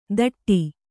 ♪ daṭṭi